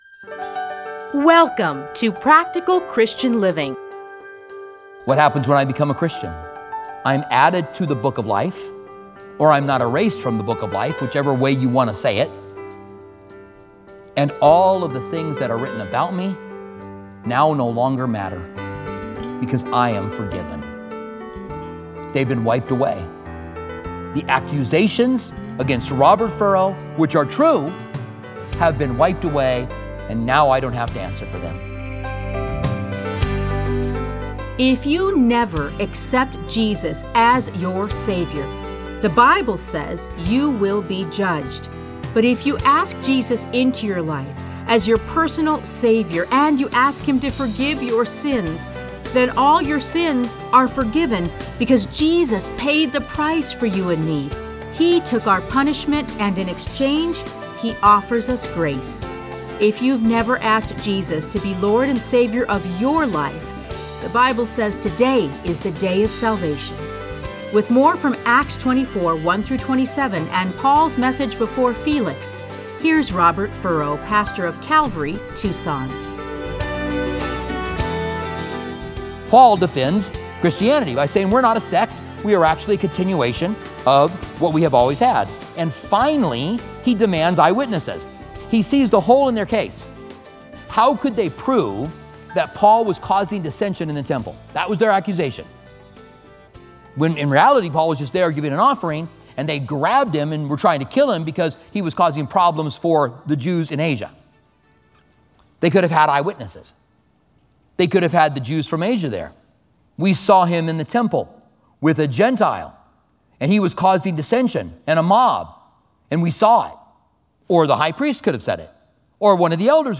Listen to a teaching from Acts 24:1-27.